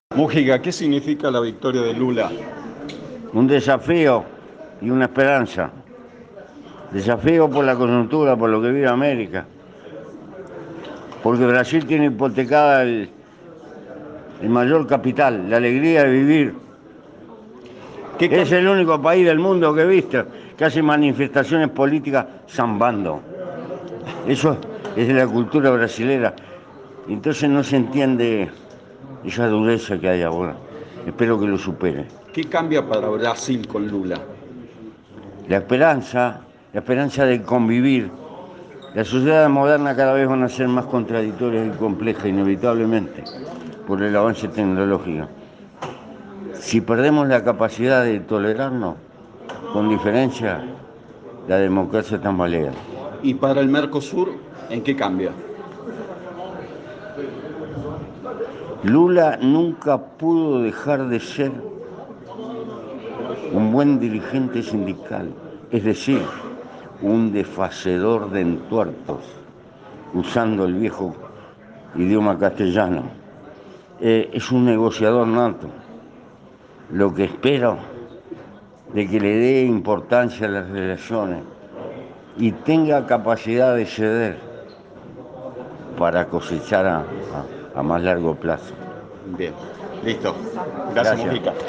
El expresidente de Uruguay dialogó con Cadena 3 y destacó que el triunfo del líder del PT cambia la manera de "convivir" en Brasil.